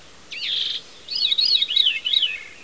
VEERY.WAV